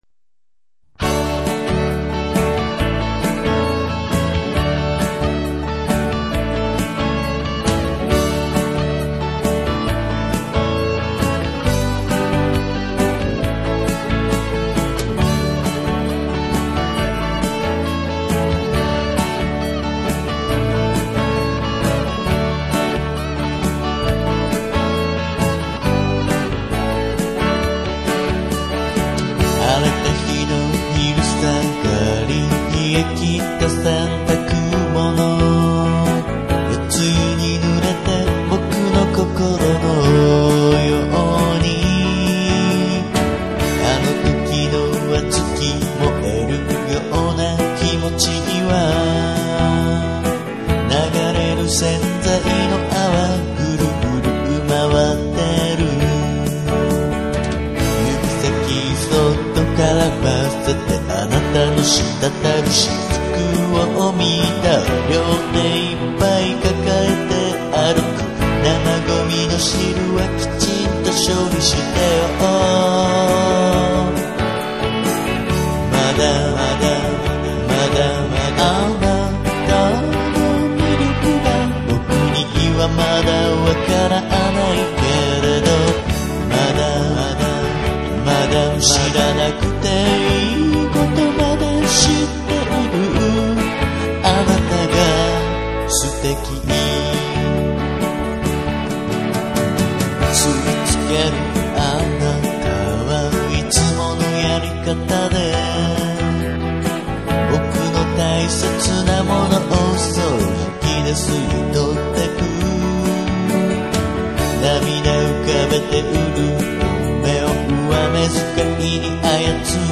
ヨーデルっぽく歌うといい感じですが、時間がなかったためちょっと音がずれています。